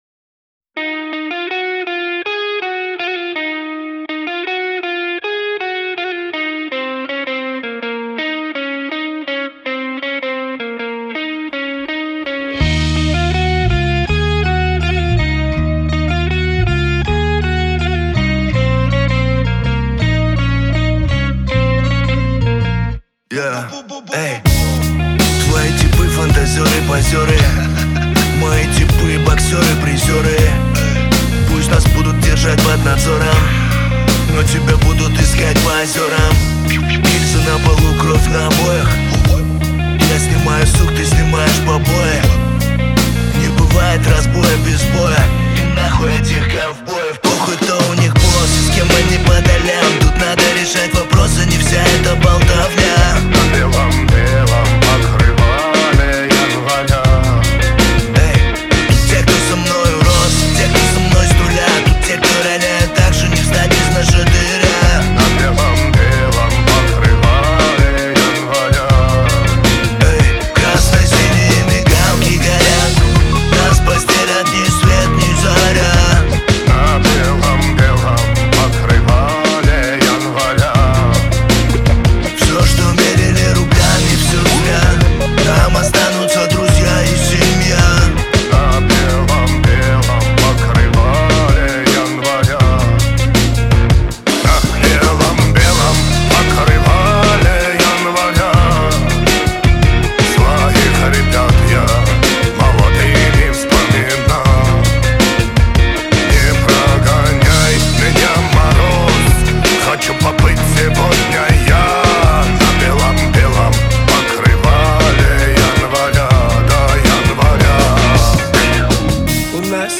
Трек размещён в разделе Русские песни.